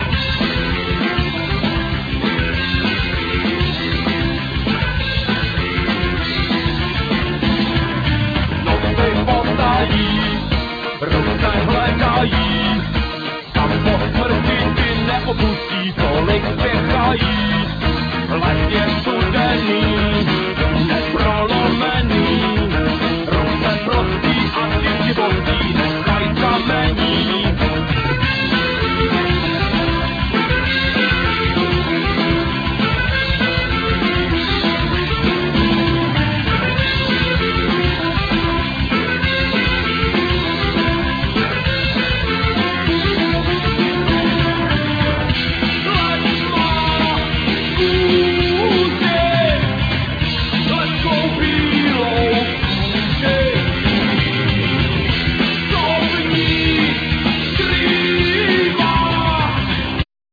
Guitar,Keyboards,Tambarine,Vocal
Tenor,sazophone,Vocal
Bass guiatar,Vocal
Drums
Horn
Trumpet
Accordion
Trombone
Tuba